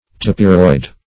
Search Result for " tapiroid" : The Collaborative International Dictionary of English v.0.48: Tapiroid \Ta"pir*oid\, a. [Tapir + -oid.]